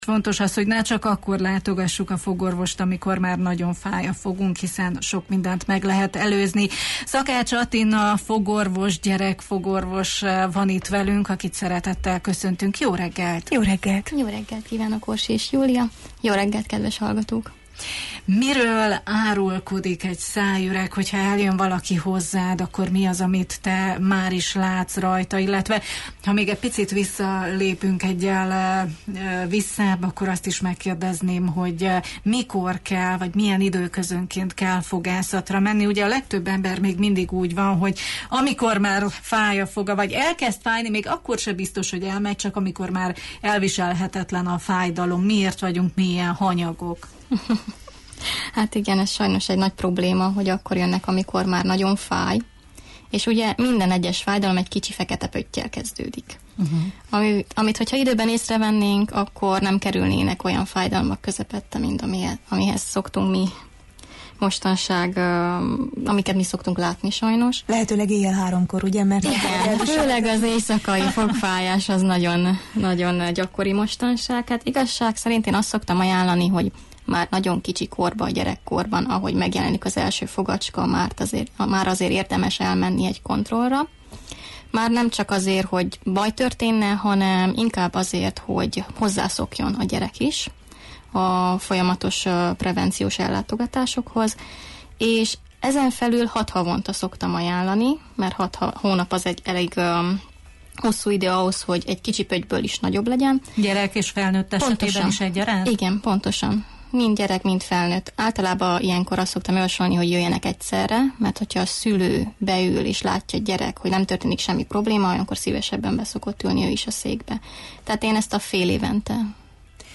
Mai meghívottunk